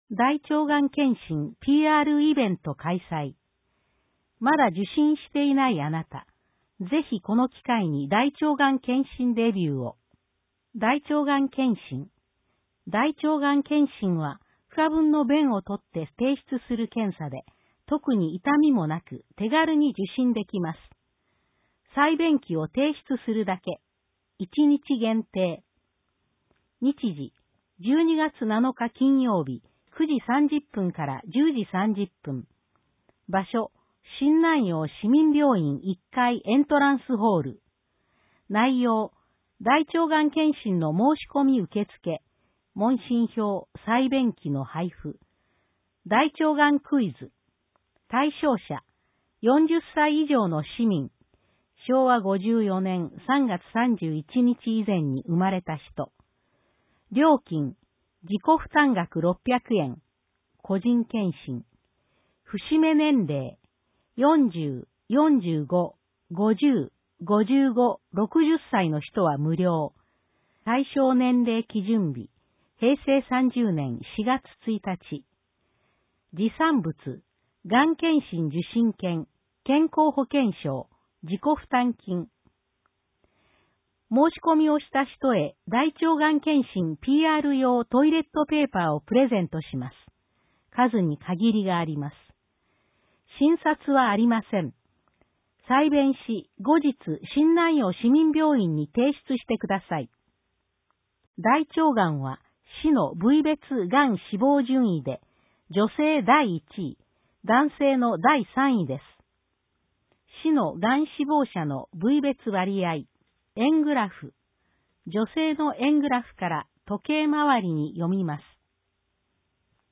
音訳広報
広報しゅうなんを、音読で収録し、mp3形式に変換して配信します。
この試みは、「音訳ボランティアグループともしび」が、視覚障害がある人のために録音している音読テープを、「周南視聴覚障害者図書館」の協力によりデジタル化しています。